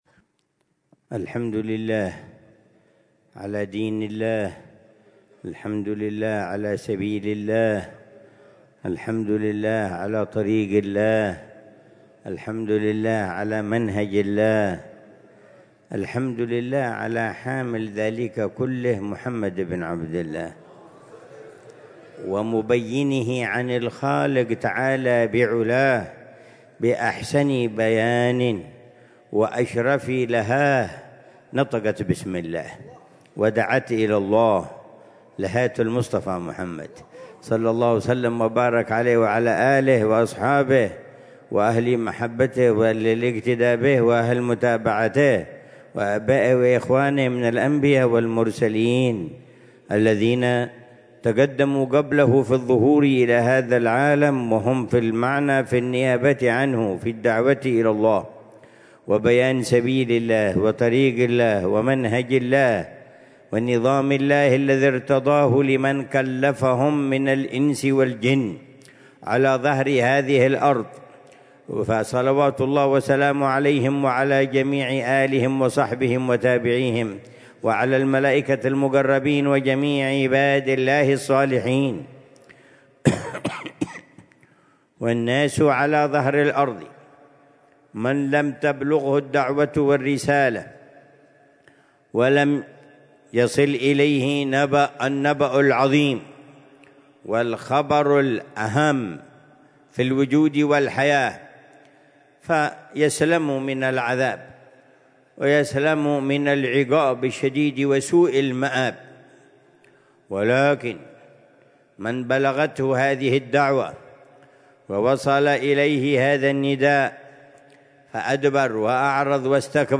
محاضرة
في دار المصطفى